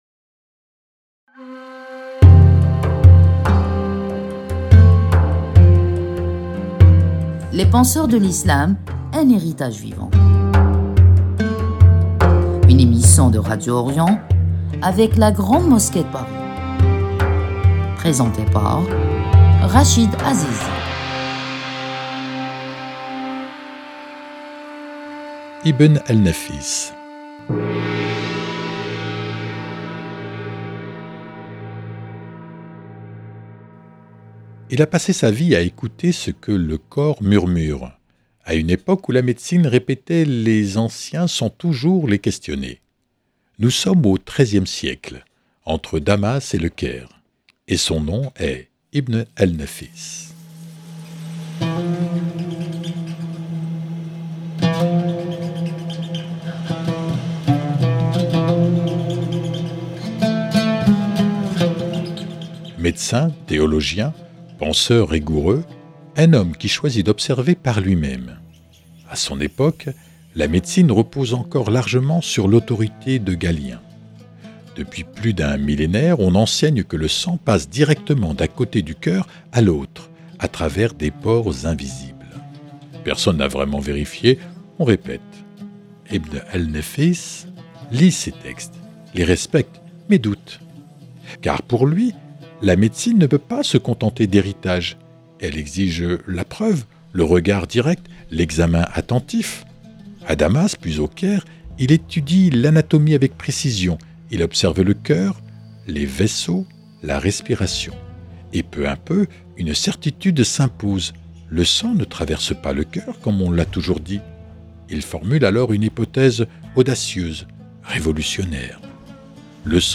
Emission de Radio Orient en partenariat avec la Grande Mosquée de Paris